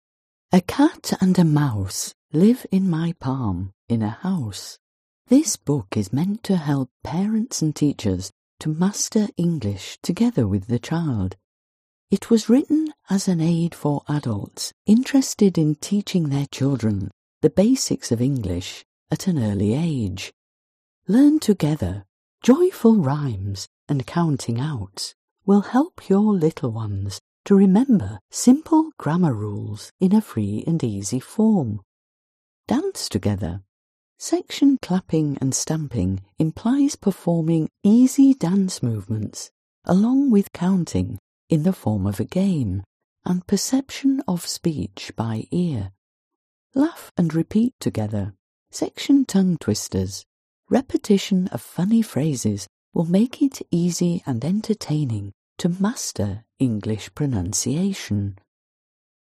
Аудиокнига Мышка и кошка живут в моей ладошке. A CAT AND A MOUSE LIVE IN MY PALM IN A HOUSE. Веселые рифмовки, считалки и скороговорки для детей на английском языке | Библиотека аудиокниг